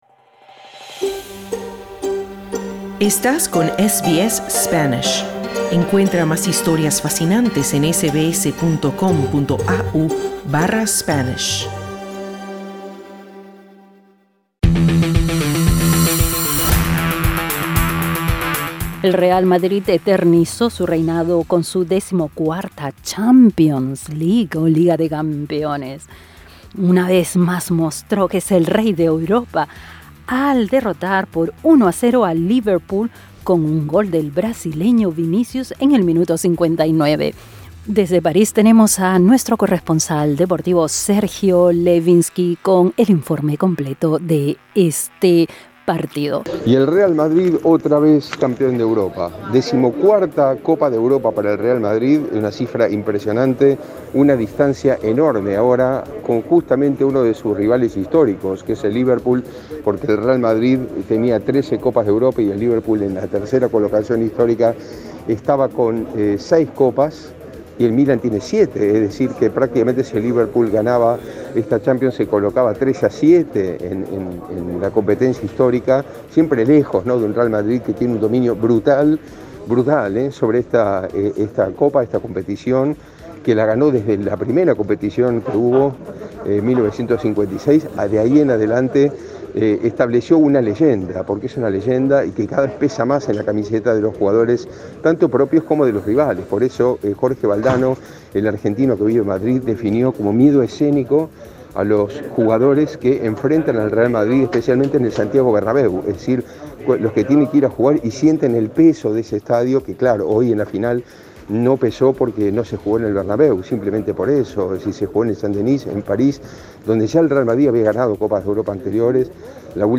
Puntos destacados: La hegemonía del Real Madrid se eterniza.